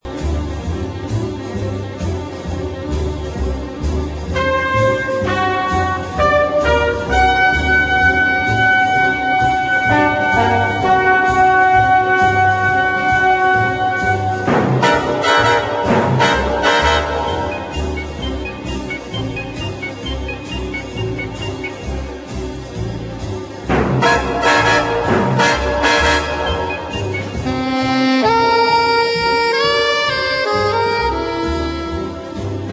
1962 thrilling medium instr.